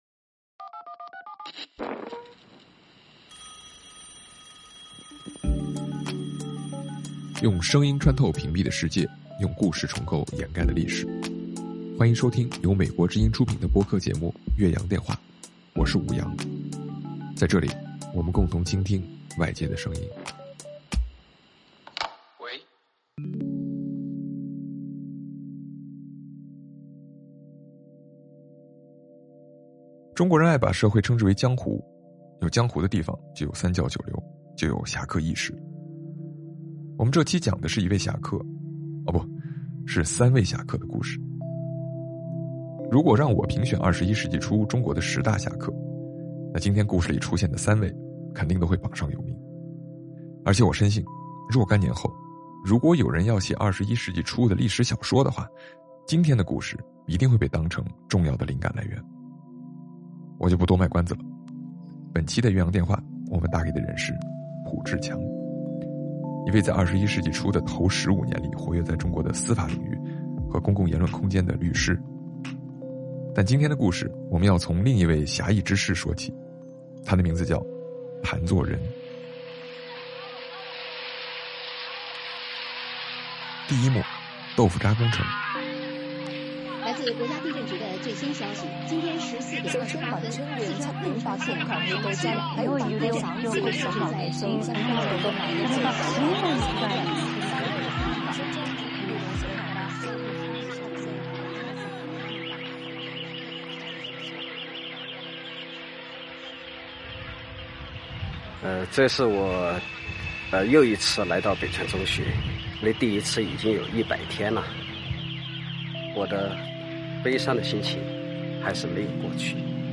本期嘉宾：浦志强